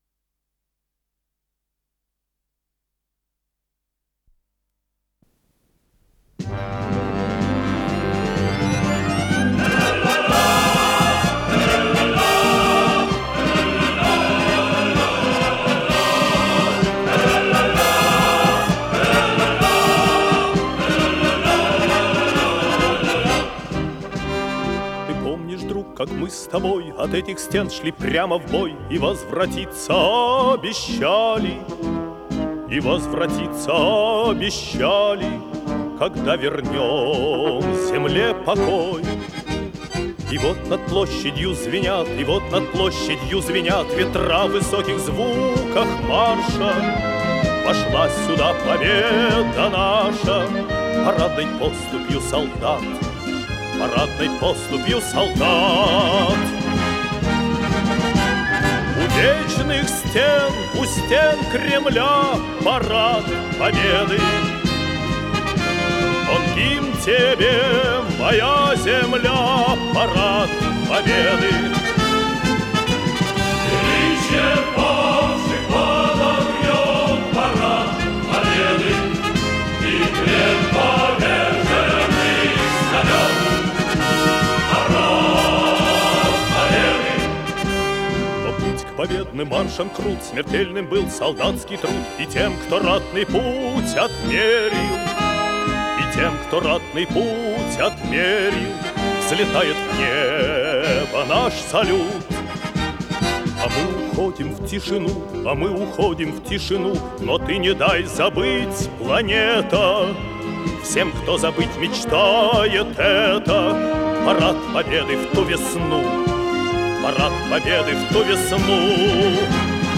с профессиональной магнитной ленты
обработка для хора
инструментовка
ВариантДубль моно